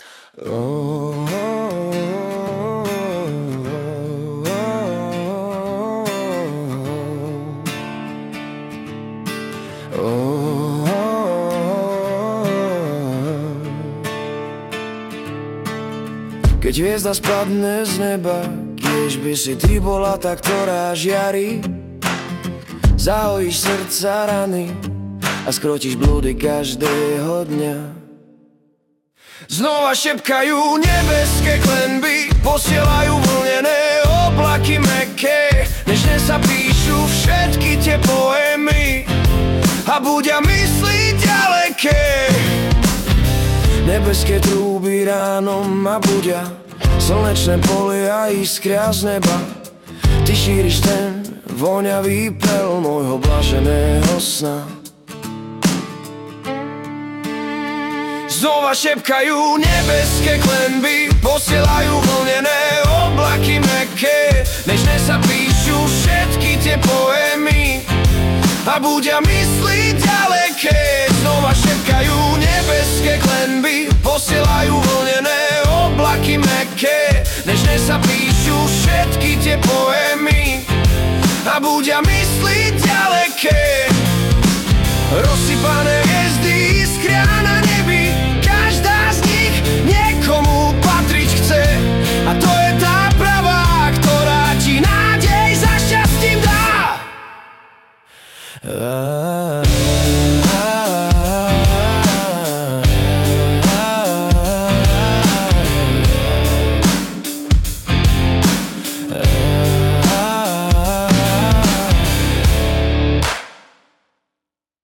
Anotace: Pieseň ktorá sa zrodila v tichu noci. O hviezdach, láske a o tom že aj pád môže byť začiatkom svetla.